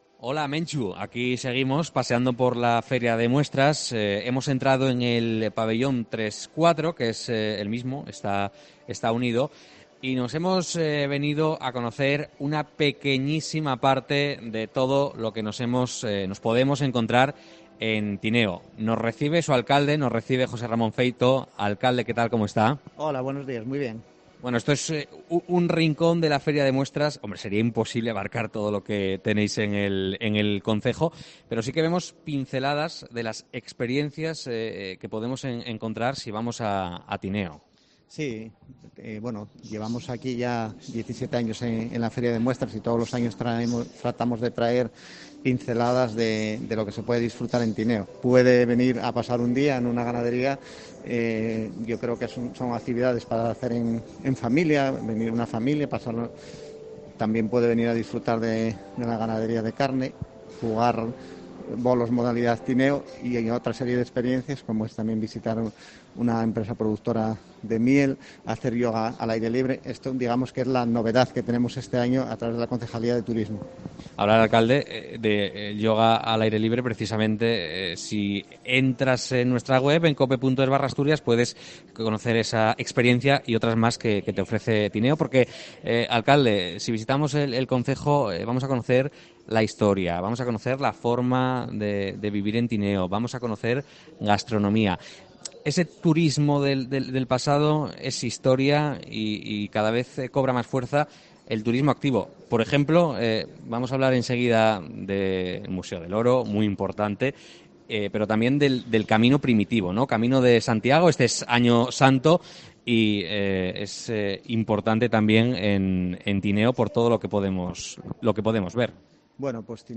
COPE EN LA FIDMA
Entrevista a José Ramón Feito, alcalde de Tineo